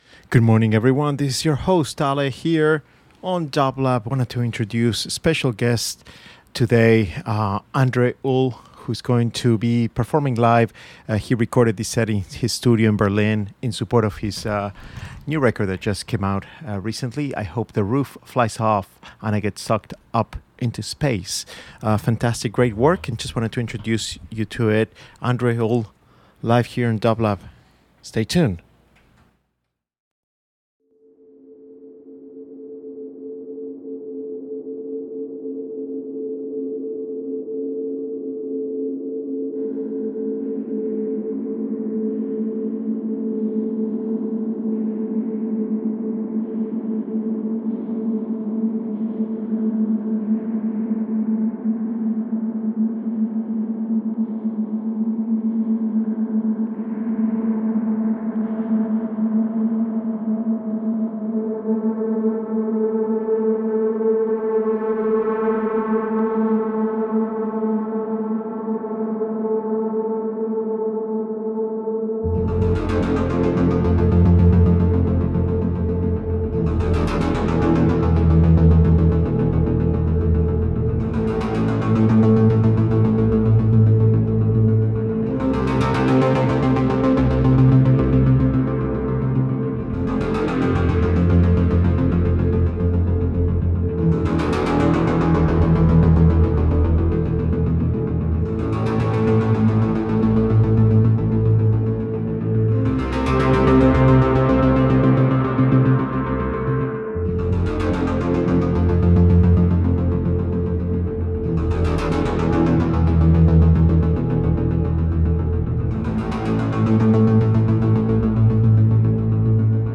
Enjoy this special live set
Electronic